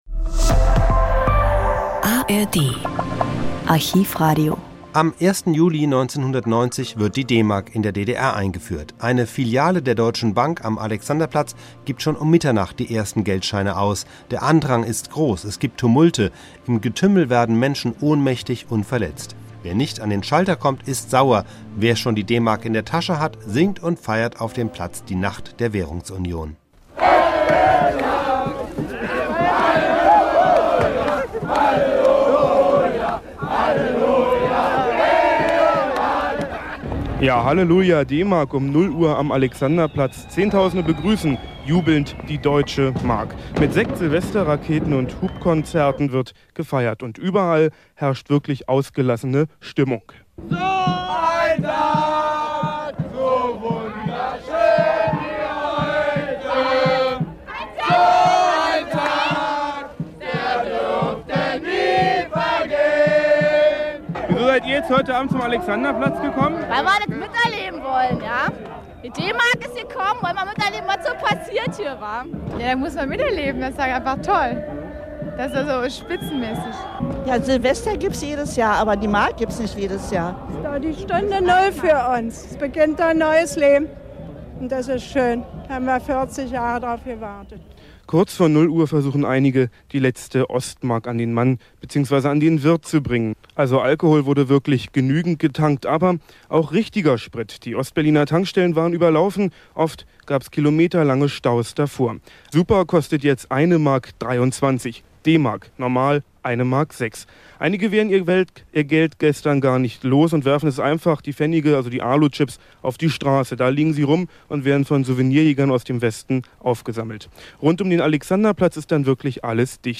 Der Südwestfunk sendet dazu nachmittags eine dreistündige Sondersendung. Hier ein Zusammenschnitt aus der ersten Stunde. Vor allem die Musik haben wir herausgeschnitten.